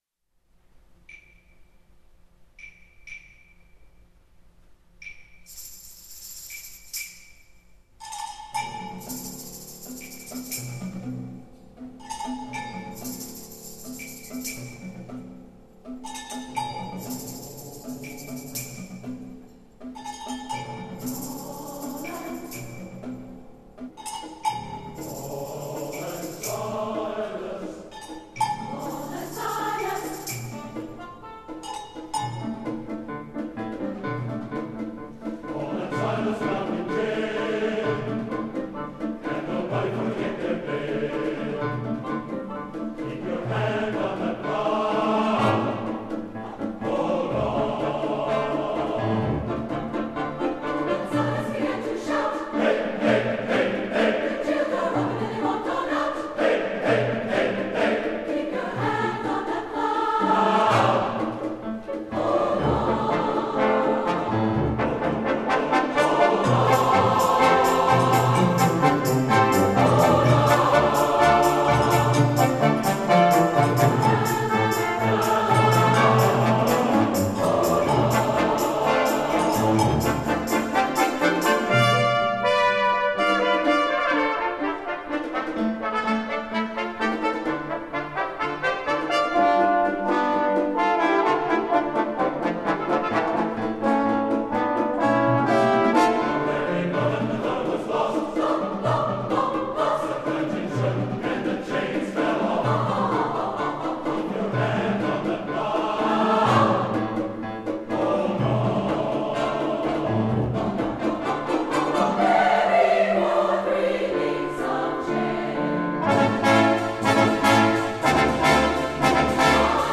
for SATB Chorus, Brass Quintet, Percussion, and Piano (2005)